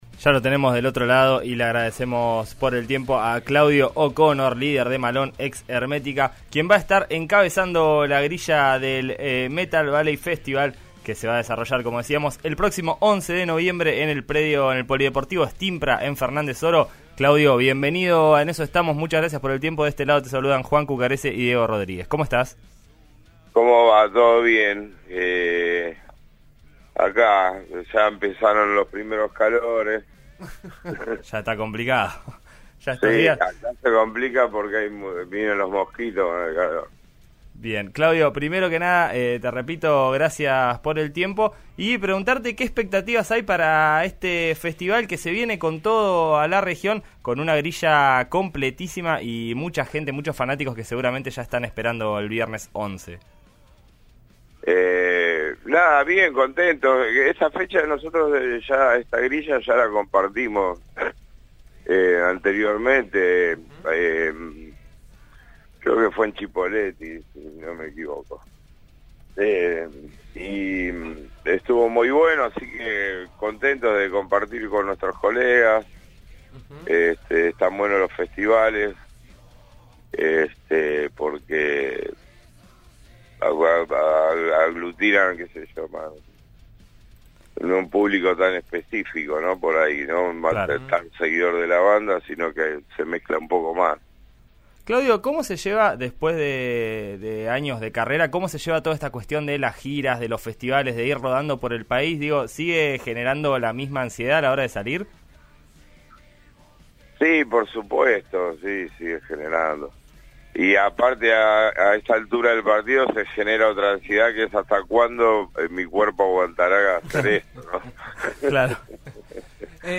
En este contexto, «En Eso Estamos» de RN RADIO dialogó con Claudio O’Connor, líder de la banda y recordado ex vocalista de Hermética, una de las referencias de este género en el país, para conocer cómo llegan a esta nueva edición del Festival.